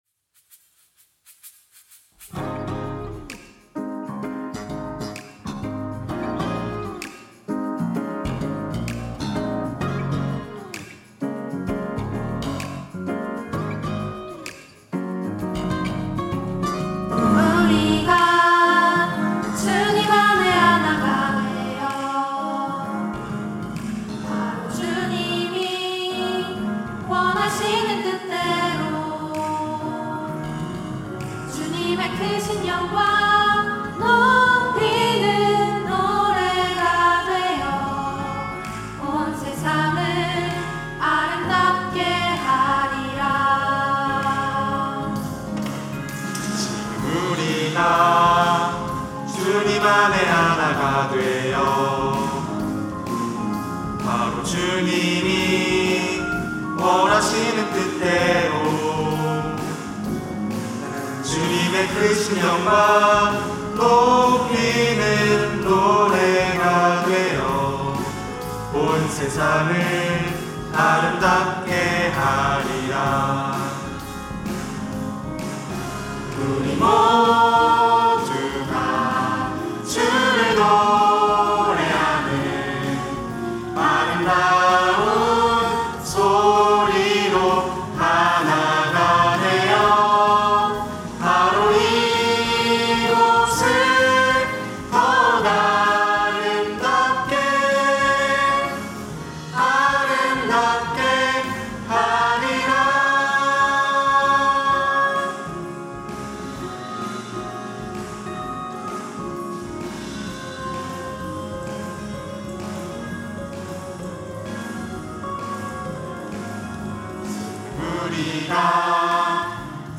특송과 특주 - 지금 우리가
청년부 28기